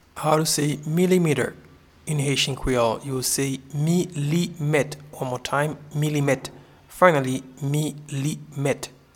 Pronunciation:
Milimeter-in-Haitian-Creole-Milimet.mp3